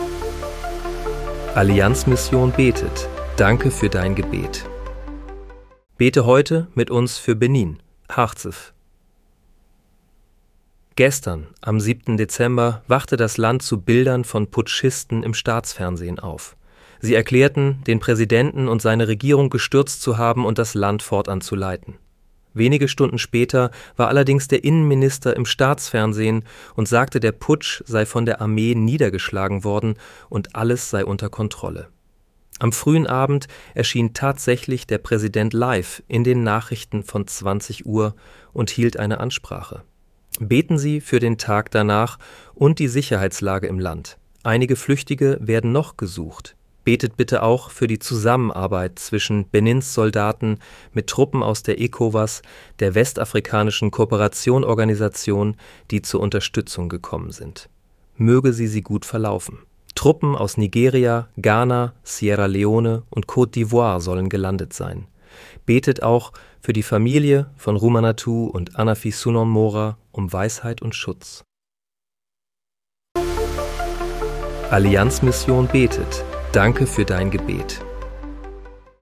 Bete am 11. Dezember 2025 mit uns für Benin. (KI-generiert mit der